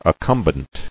[ac·cum·bent]